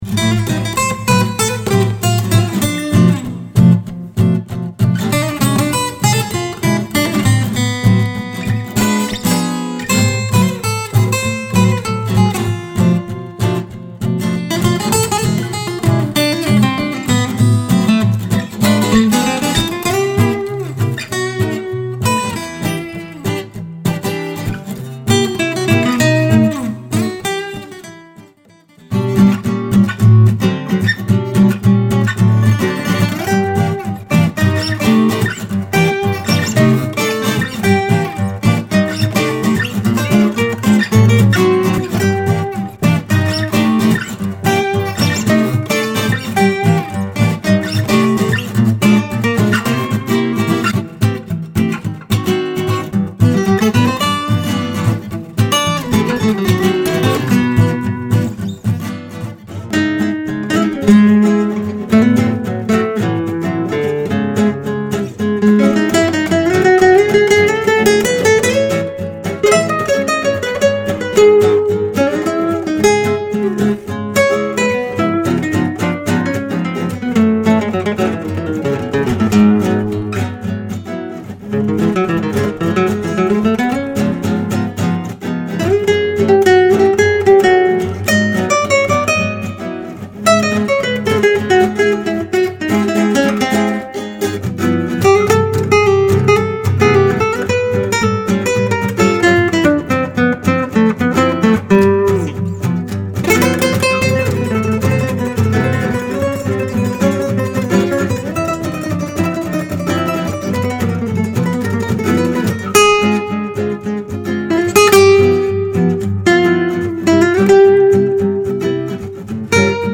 Note: this pack contains dry guitar loops only.
Gypsy-Swing-Guitars-Vol-1.mp3